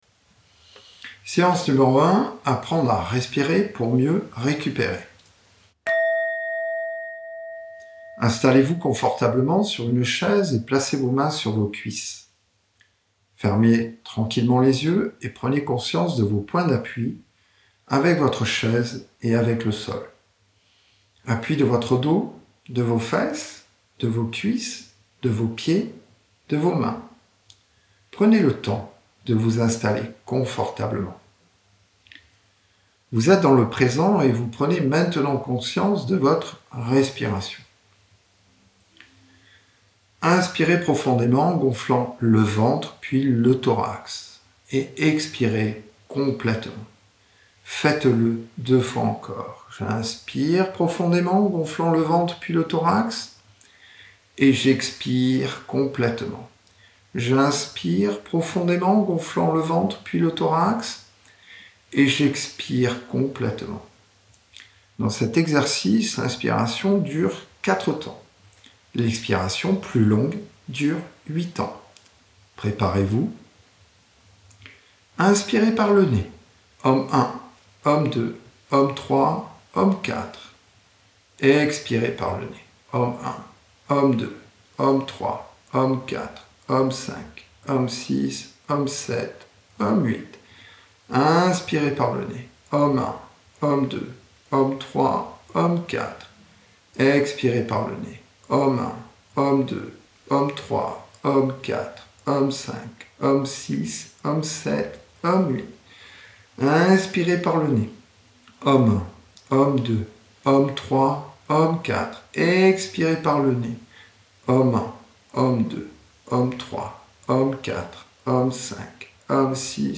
9 séances de méditation, de 5 à 9 minutes, sur les thèmes suivants :